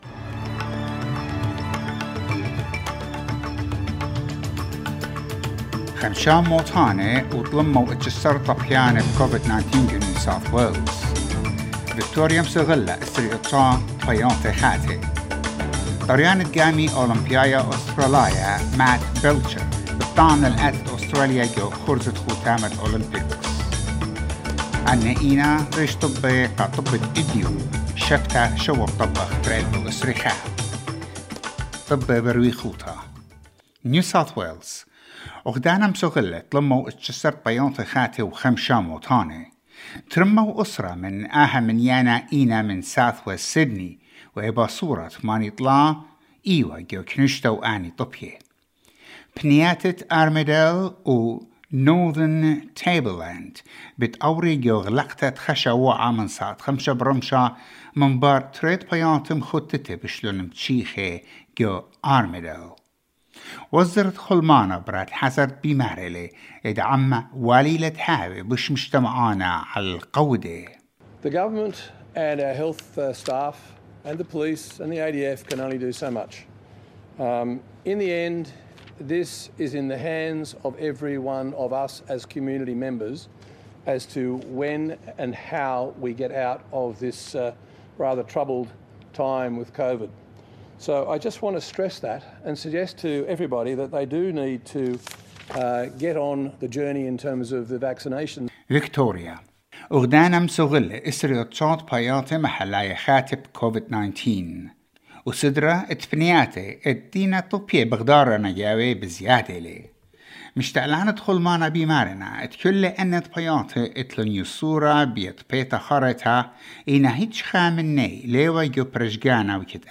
SBS NEWS IN ASSYRIAN 8 JULY 2021